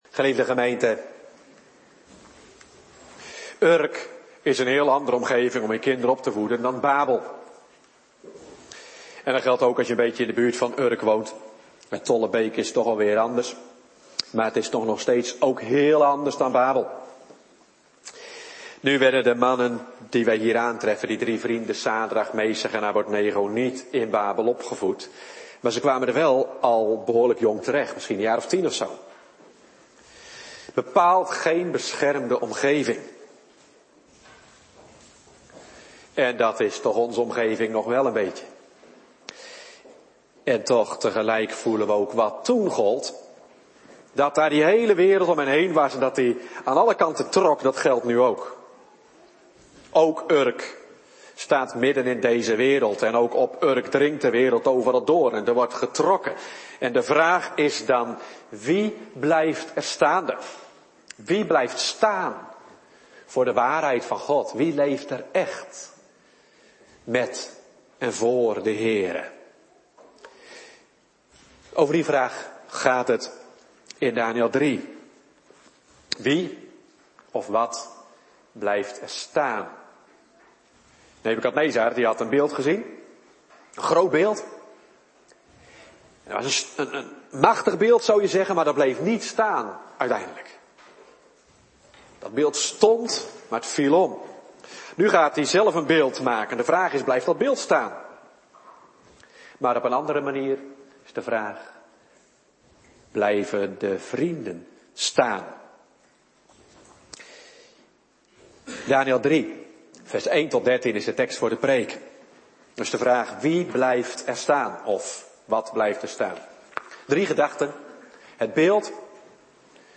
Soort Dienst: Bediening Heilige Doop